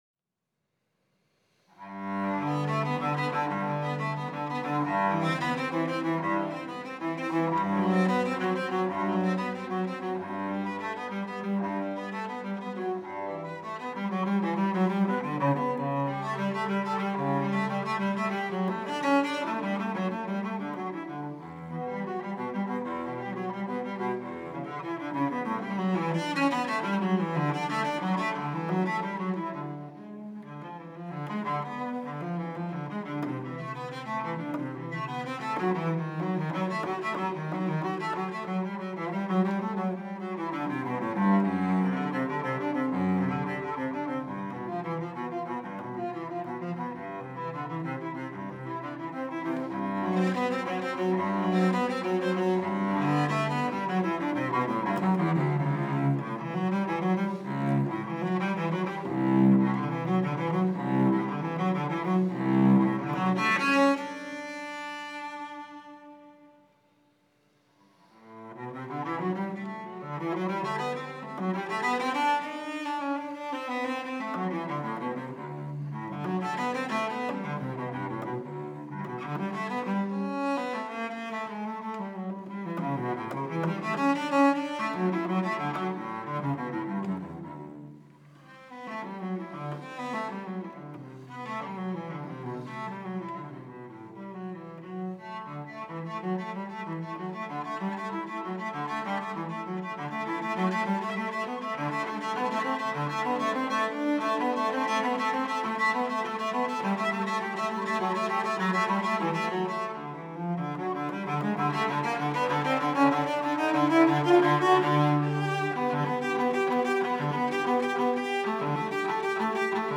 Sanctuary-January-24-audio.mp3